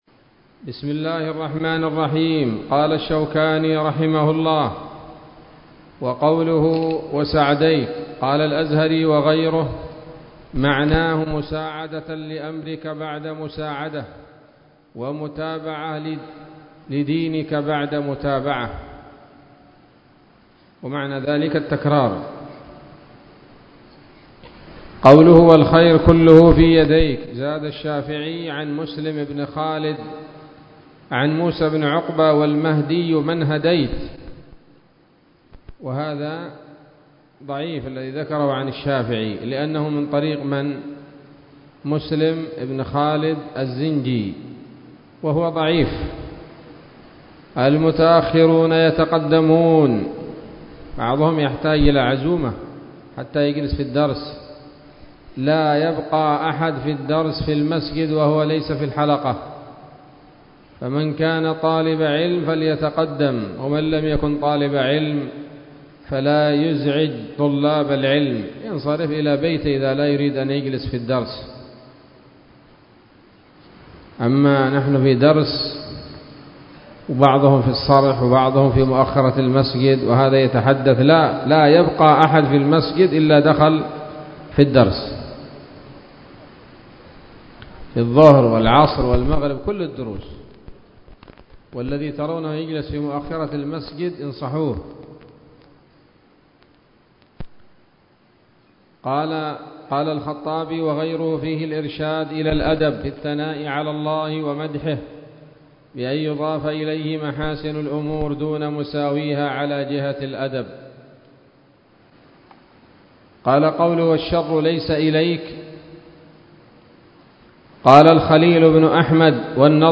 الدرس السابع عشر من أبواب صفة الصلاة من نيل الأوطار